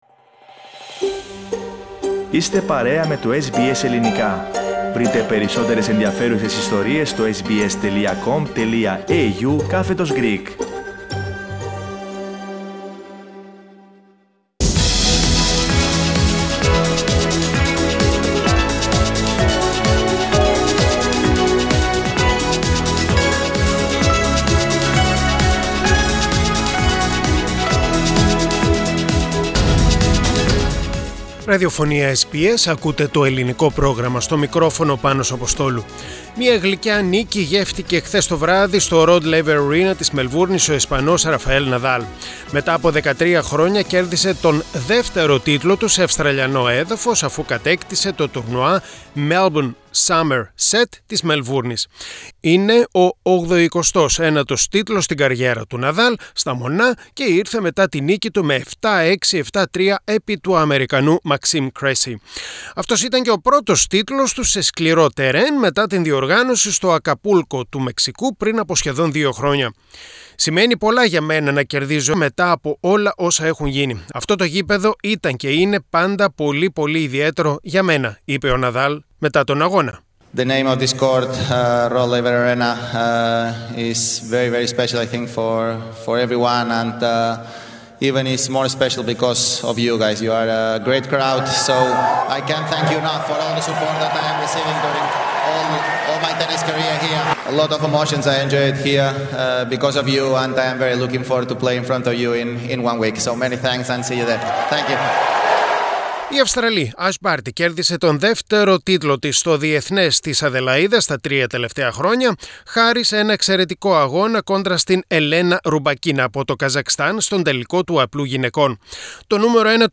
Αθλητικό Δελτίο με αντισφαίριση και ποδόσφαιρο.